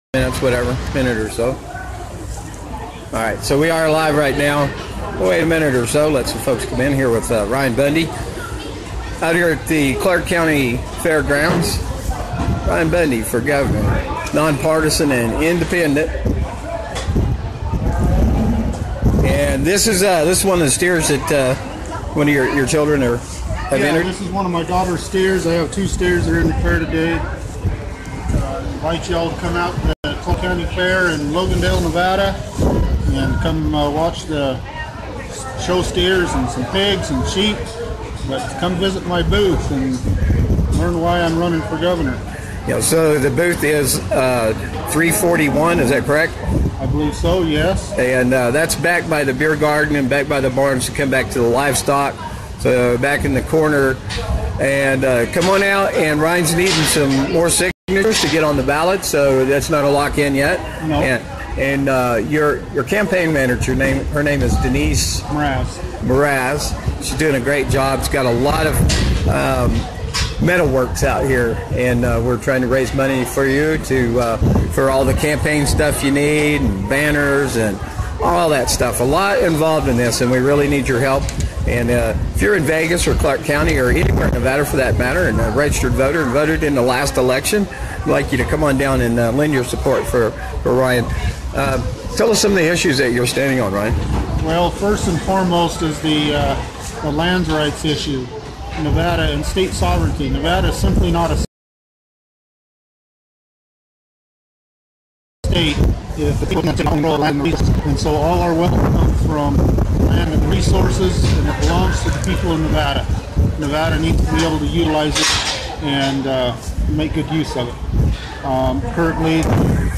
Ryan Bundy for Governor of Nevada from the Clark County Fairgrounds